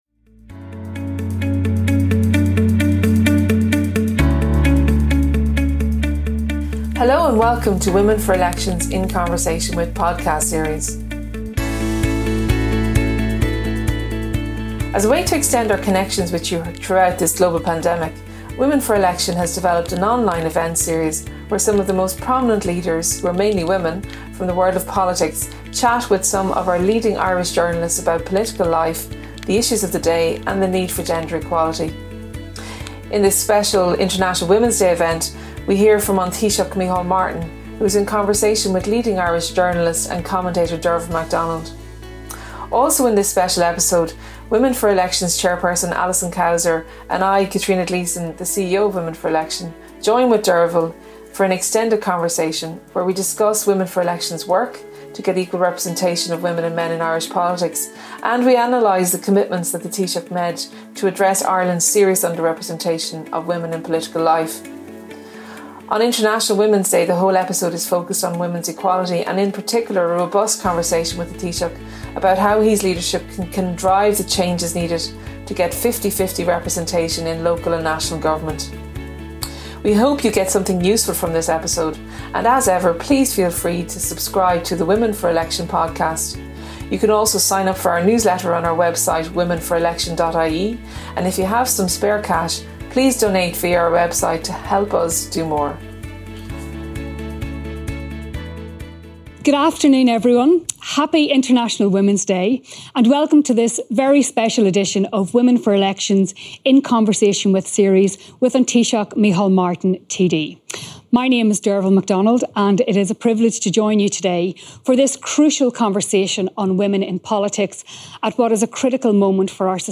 As a way to stay connected and engaged with our community, Women for Election developed an online series where some of the most prominent women in Irish politics chatted to some of our leading journalists about political life, the impact of Covid and the need for gender equality. These are the recordings from the conversations.